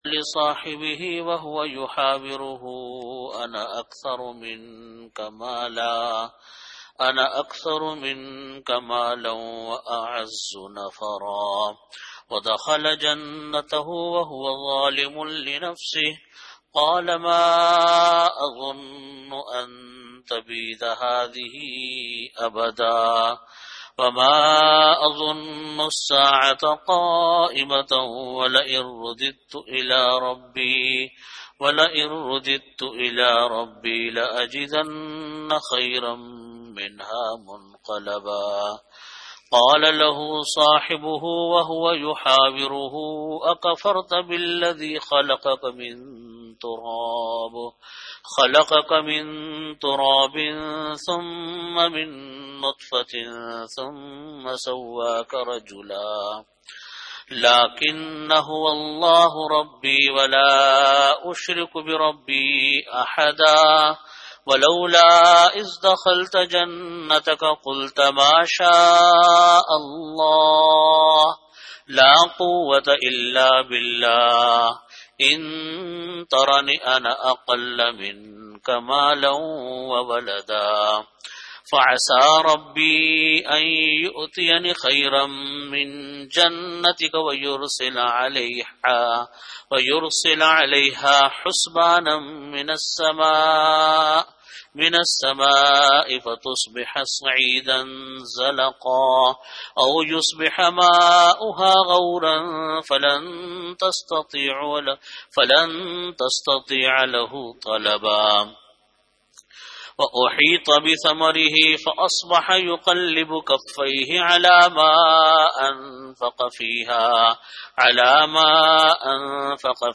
An Islamic audio bayan
Delivered at Jamia Masjid Bait-ul-Mukkaram, Karachi.